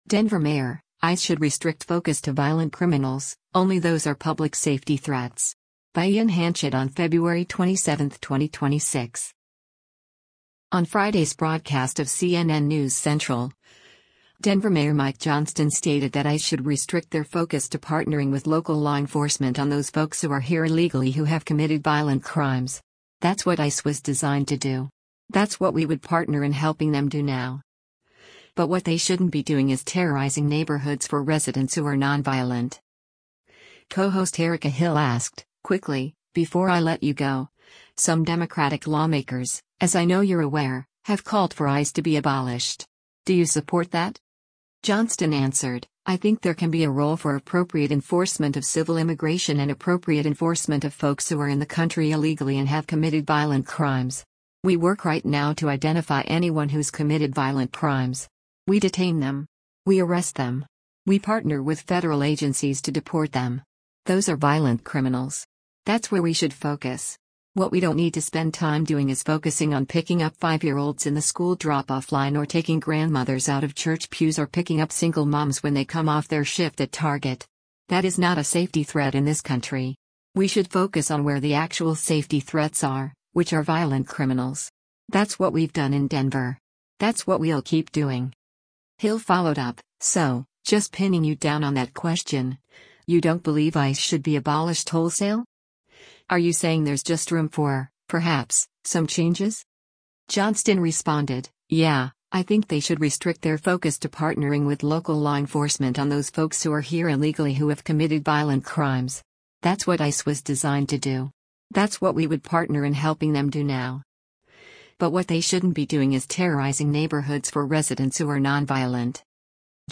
On Friday’s broadcast of “CNN News Central,” Denver Mayor Mike Johnston stated that ICE “should restrict their focus to partnering with local law enforcement on those folks who are here illegally who have committed violent crimes.
Co-host Erica Hill asked, “Quickly, before I let you go, some Democratic lawmakers, as I know you’re aware, have called for ICE to be abolished. Do you support that?”